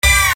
Hit 006.wav